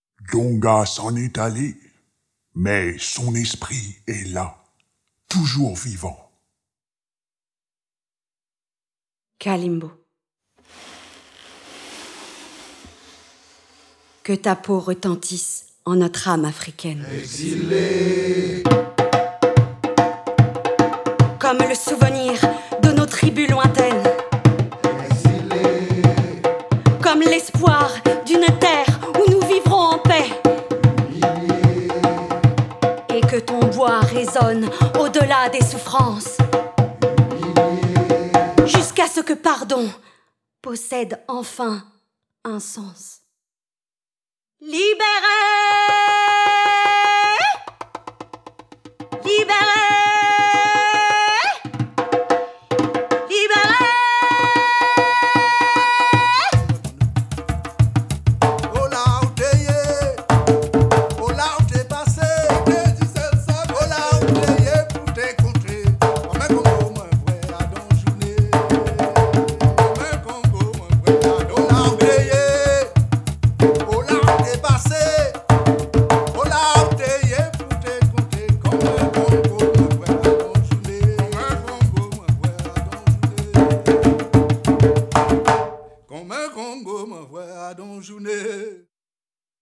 Voix off
Extrait du conte musical "kalimbo"
7 - 100 ans - Contralto